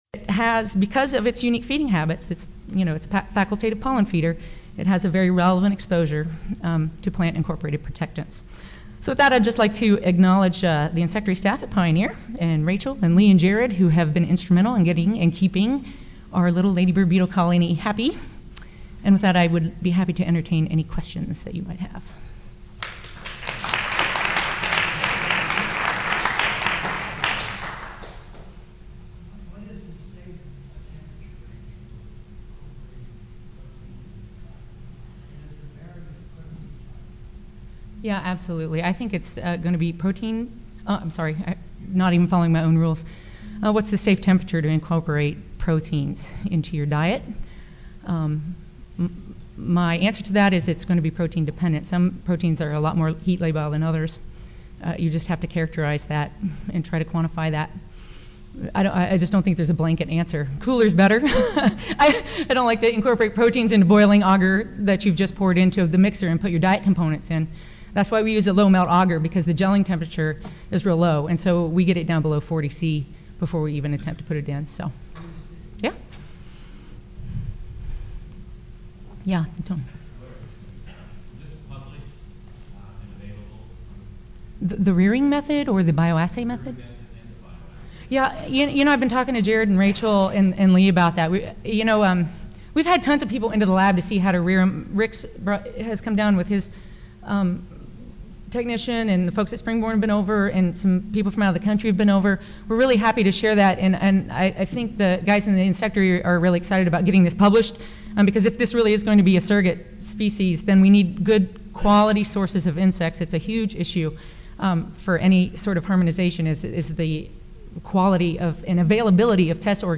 10:29 AM Recorded presentation Audio File 0276 Helicoverpa zea : Population genetics, molecular markers, and their utilization in migratory studies